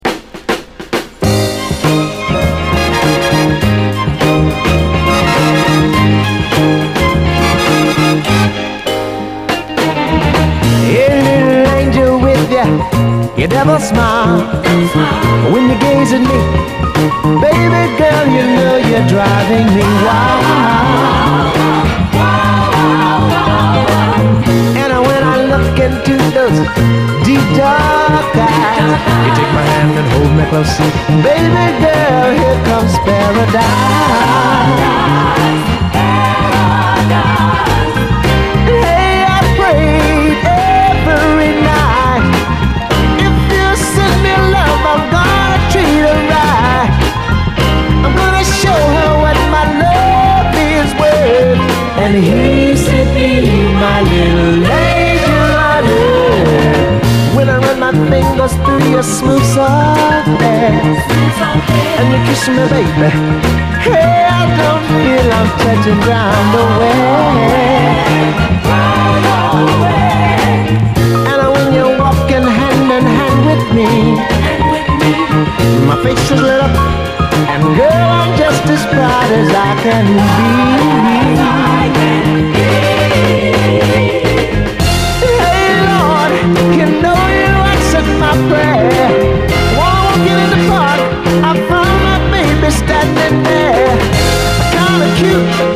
SOUL, 70's～ SOUL, 7INCH
シカゴ・ソウルの神髄を行く、傑作ゴージャス・ソウル！
イントロのドラム・フィルインから完璧、派手なブラス・アレンジなど全てが鮮やかな傑作ゴージャス・ソウルです！